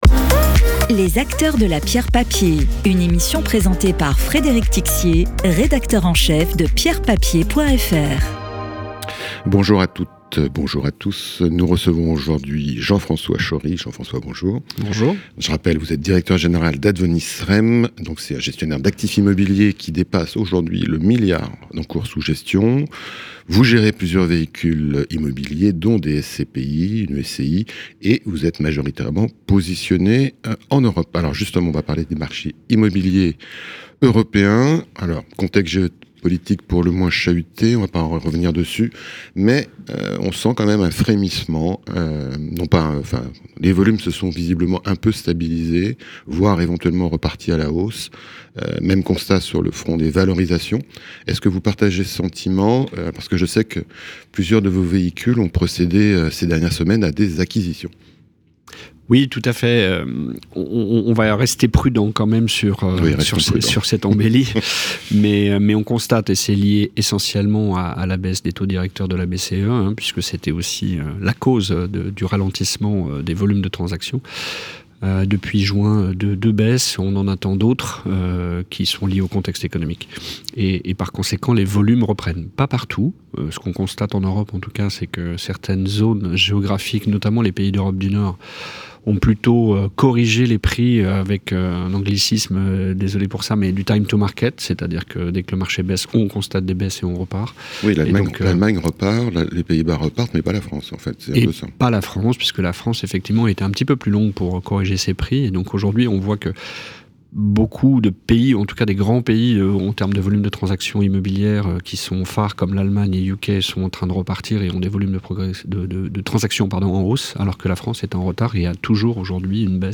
Podcast d'expert
Interview.